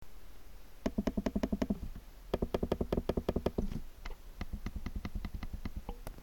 Dešiniajame „Lift“  šone įrengti pagrindiniai mygtukai yra itin tylūs, o ratukas priskiriamas SmartWheel grupei.